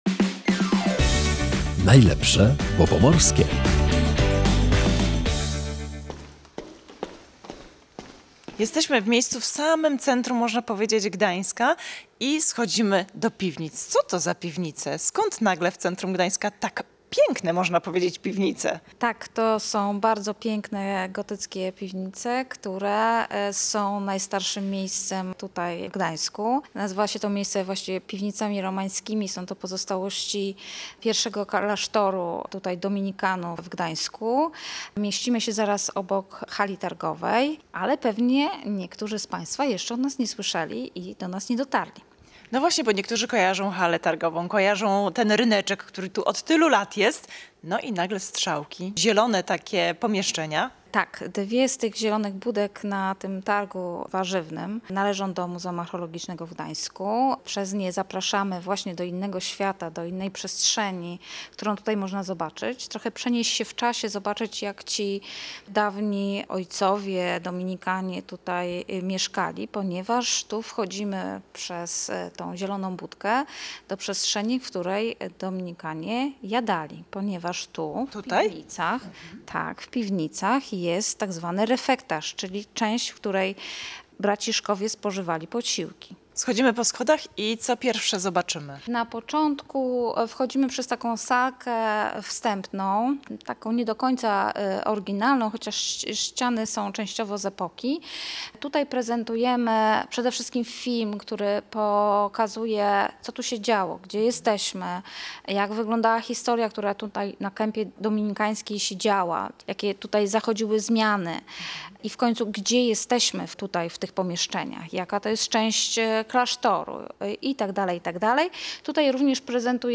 W audycji „Najlepsze, bo pomorskie” zabraliśmy słuchaczy do najstarszego miejsca w Gdańsku, które jest dostępne dla zwiedzających. To Piwnica Romańska, która znajduje się między Gdańską Halą Targową a kościołem Św. Mikołaja.